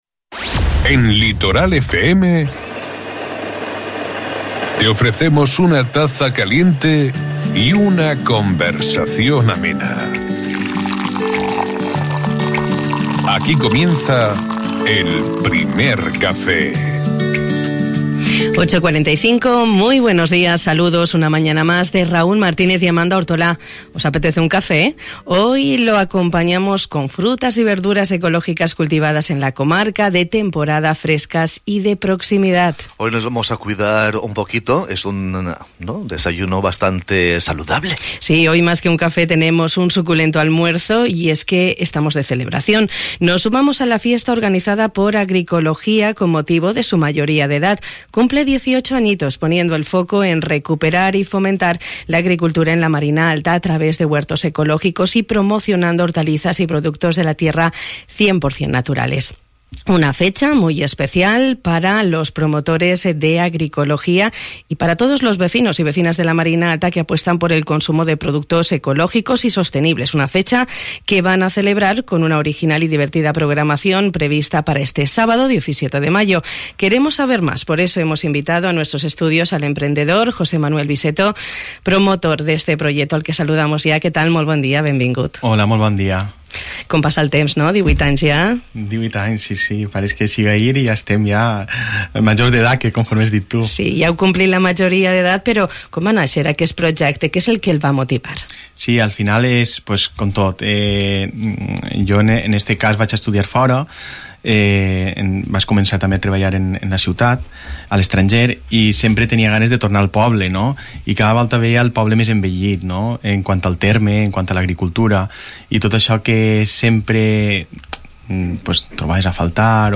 Una entrevista que nos ha servido para hacer un recorrido por la trayectoria de este proyecto desde sus inicios hasta el día de hoy.